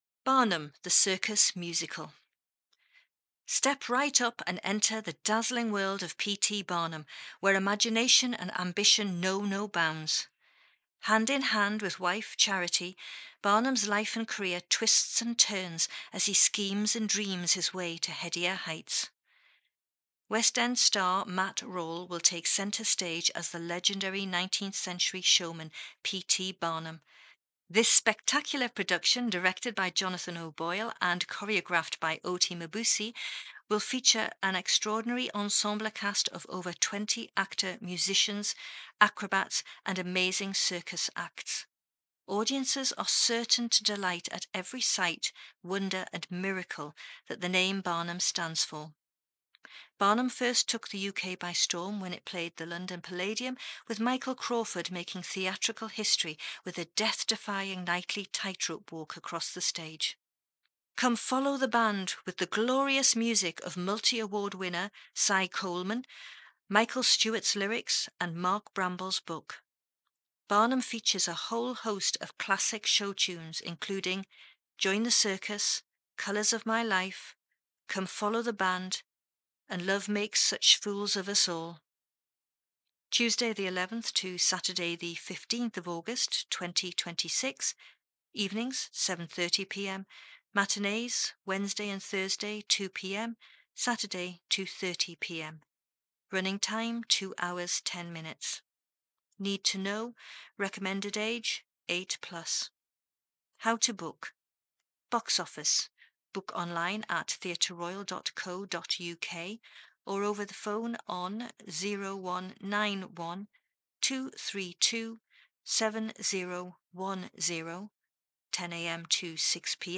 Listen to the Barnum Audio Flyer